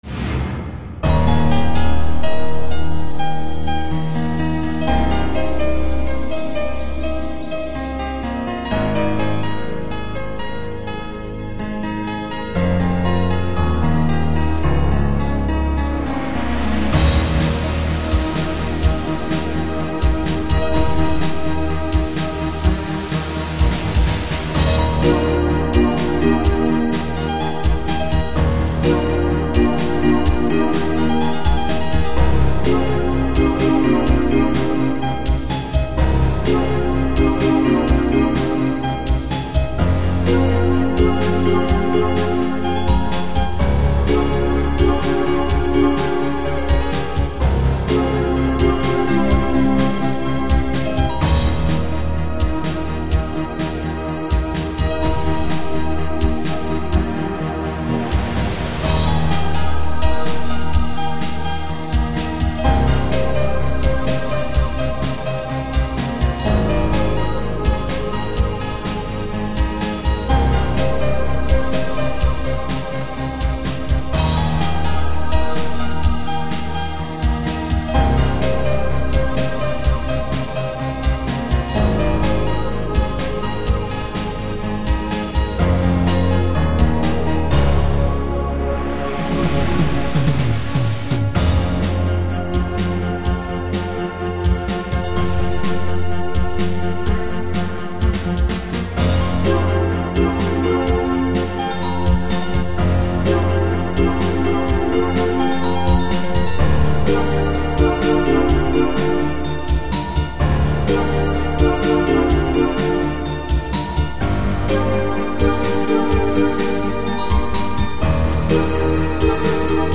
Easy-listening